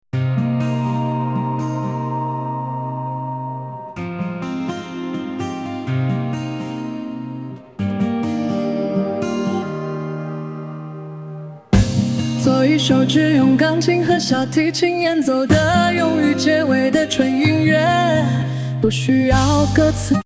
作一首只用钢琴和小提琴演奏的用于结尾的纯音乐，不需要歌词